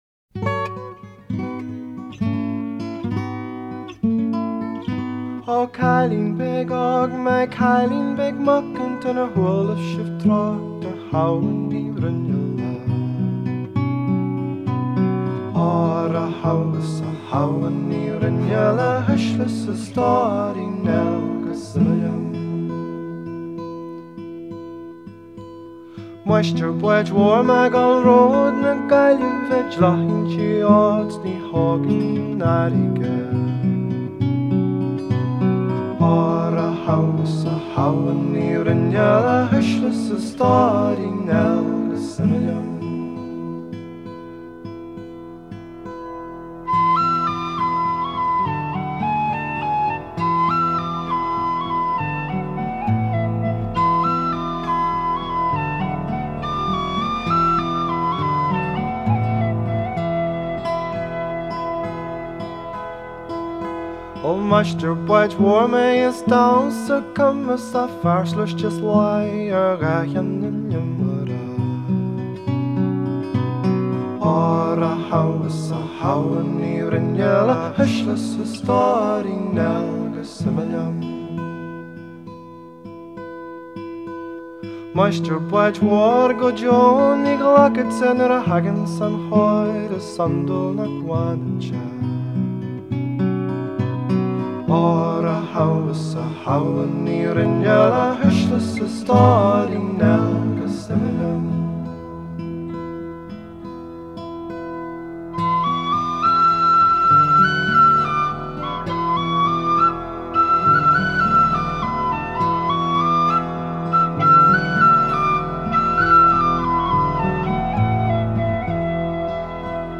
lead vocals & harmony vocals; guitar & whistle
harmony vocals
pipe-drone.